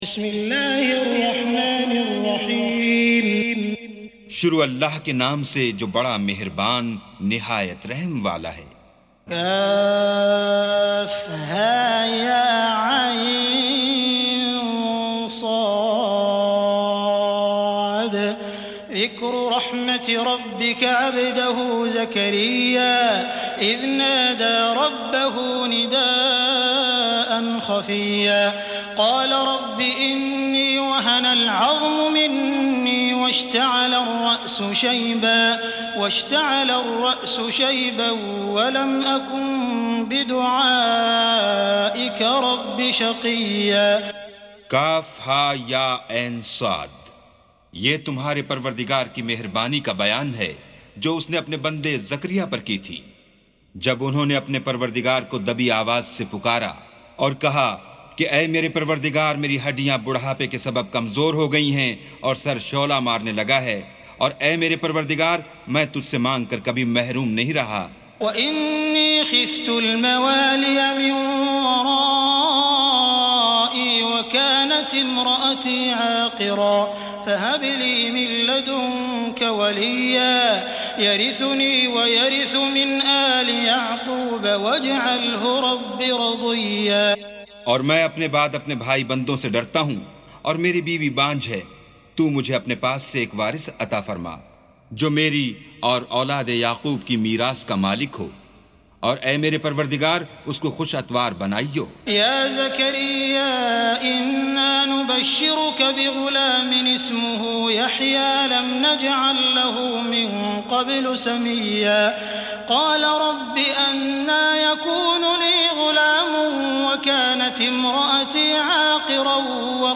سُورَةُ مَرۡيَمَ بصوت الشيخ السديس والشريم مترجم إلى الاردو